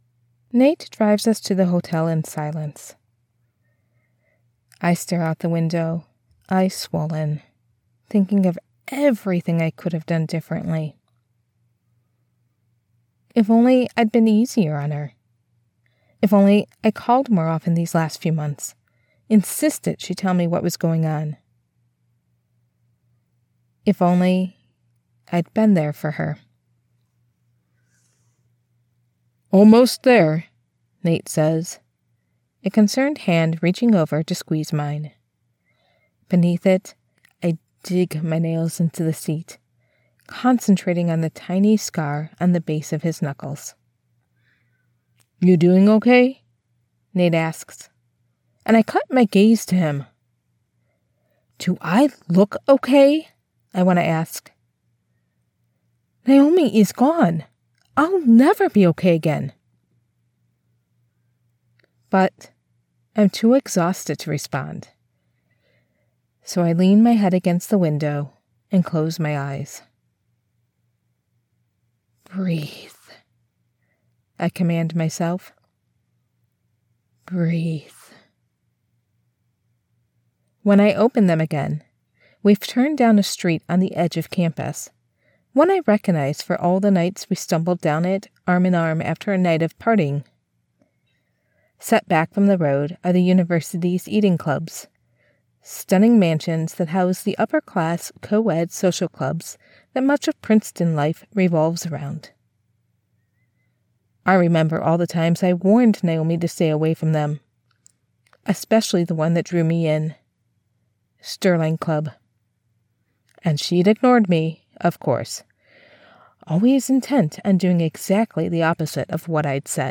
Home Studio Specs: AT2020USB+ Cardioid Condenser USB Mic, Audacity, DropBox or WeTransfer.
Suspense - Dark Academia | 1st | F/M | Grief and College Campus Secrets
Warm, Grounded, Midwest accent
Clear and intuitive